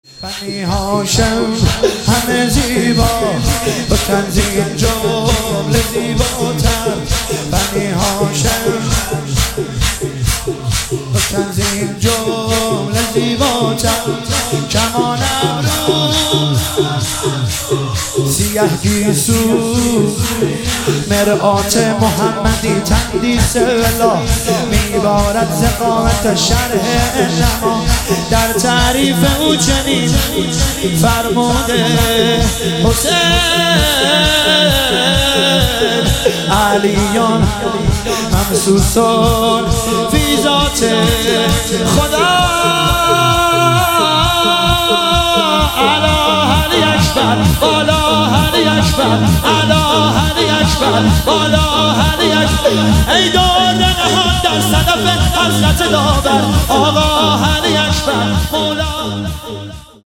ایام محسنیه 99 | هیئت ام ابیها قم